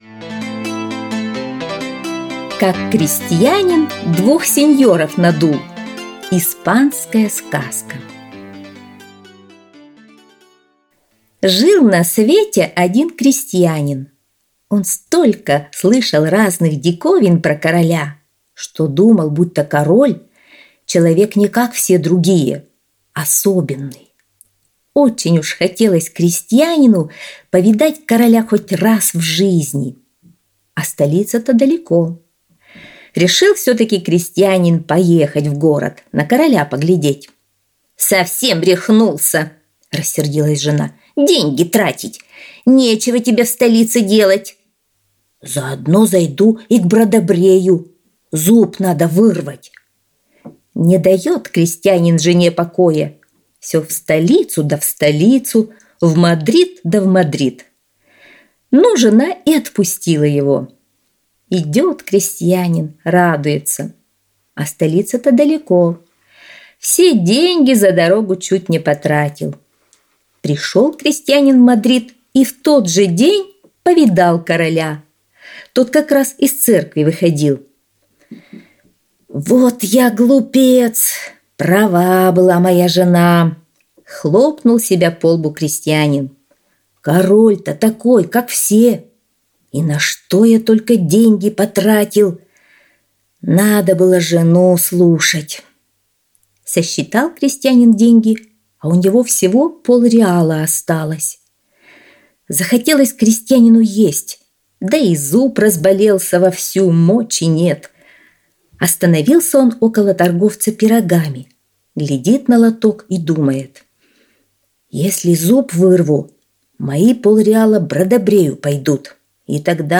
Аудиосказка «Как крестьянин двух сеньоров надул»